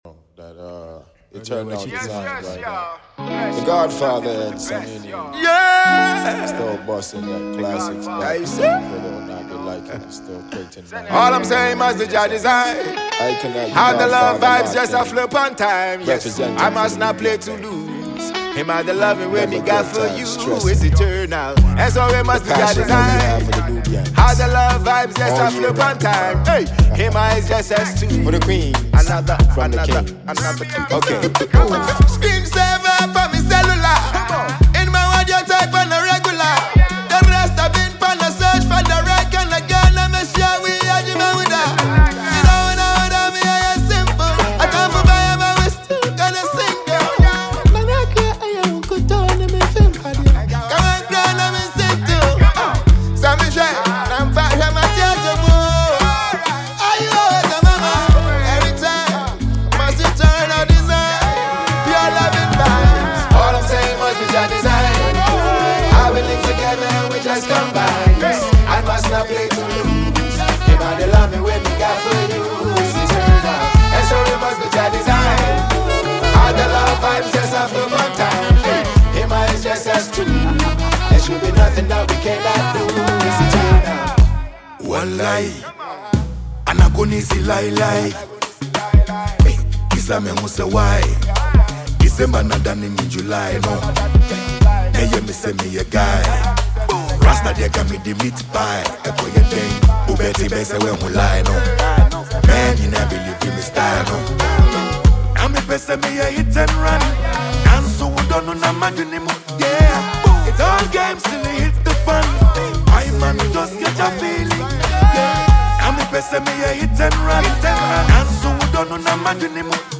carries a calm yet uplifting vibe
• Genre: Reggae / Afro-Soul